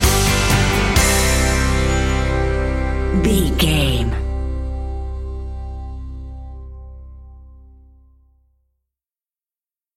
Ionian/Major
electric guitar
drums
bass guitar